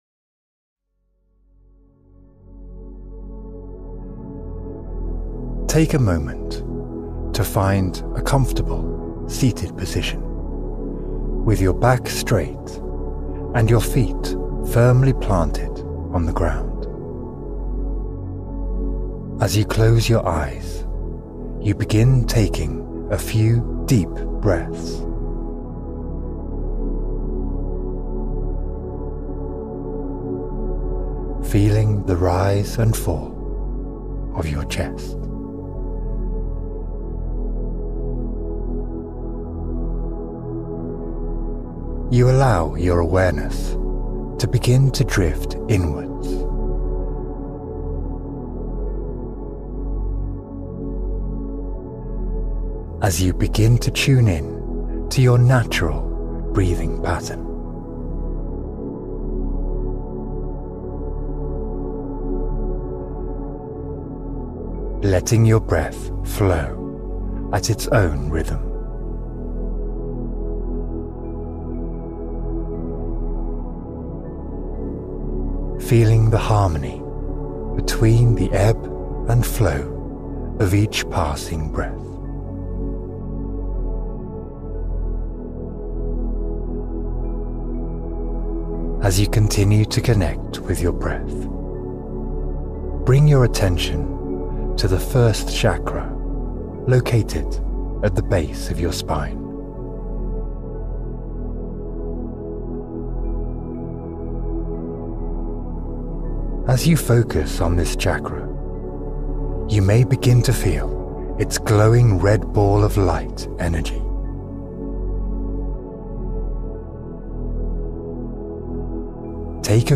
Balance Chakras and Feel Revitalized — Guided Meditation for Energy Harmony